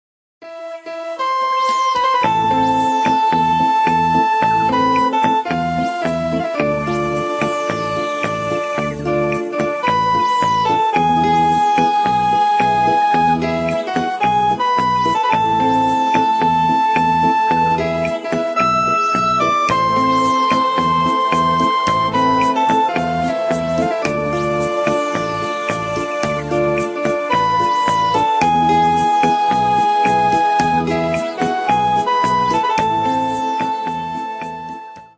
Рингтон Армянская народная песня